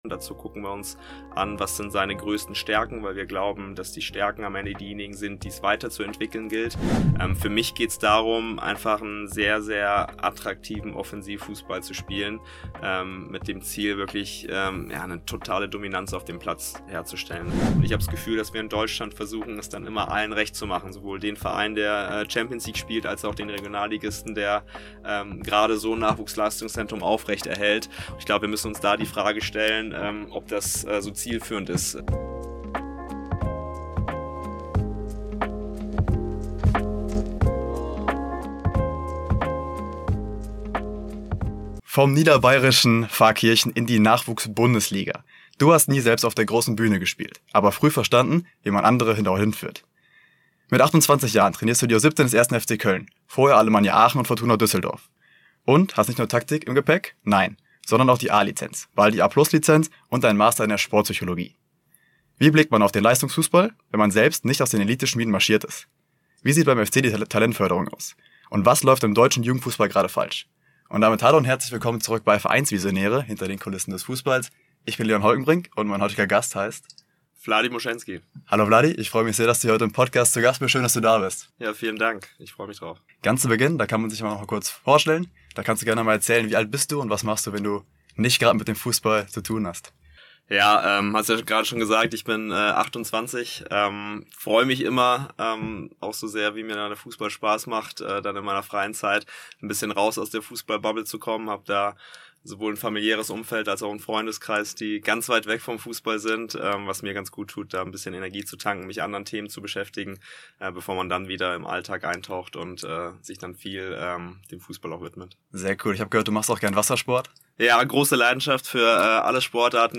Ein Gespräch über Haltung, Entwicklung und die Frage, wie man Fußballtrainer wird, ohne je Profi gewesen zu sein.